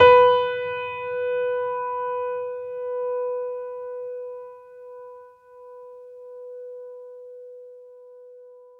piano-sounds-dev
b3.mp3